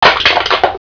CRUSH2.WAV